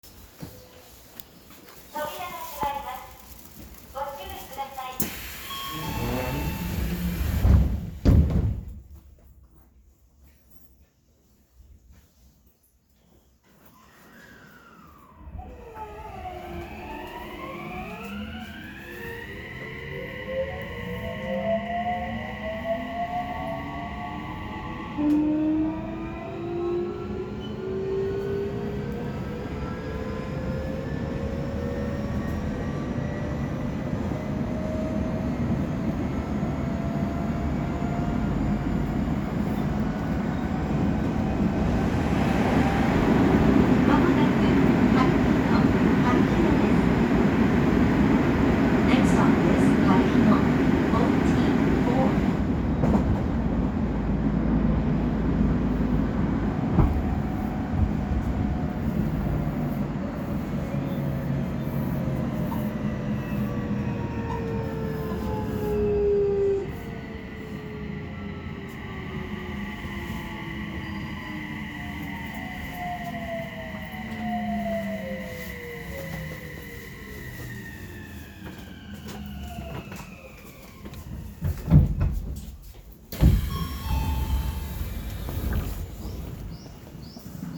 【多摩線】黒川→はるひ野
こちら、ワイドドア車を含む初期の3000形の走行音。都営6300形や各種LRT車両でお馴染みの三菱モーター。音量も大きいのでなかなか聞き応えがあります。